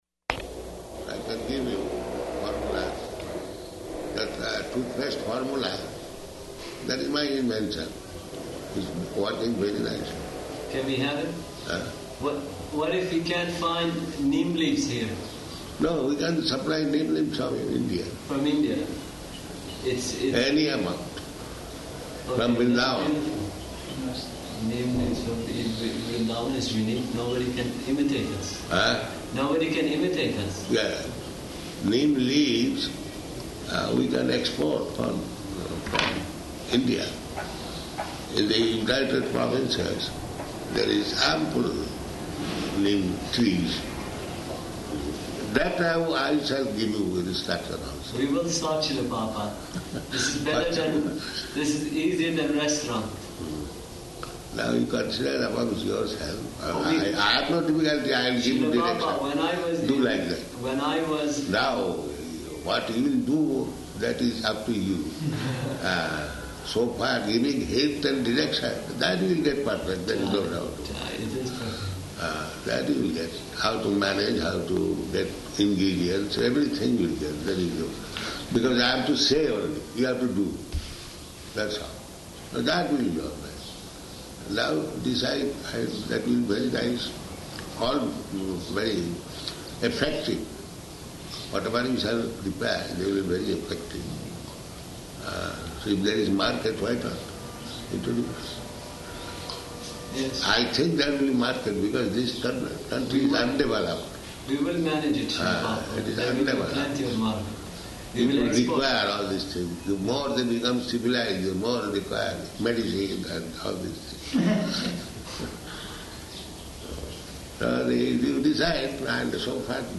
Room Conversation
Room Conversation --:-- --:-- Type: Conversation Dated: August 12th 1976 Location: Tehran Audio file: 760812R1.TEH.mp3 Prabhupāda: I can give you formula.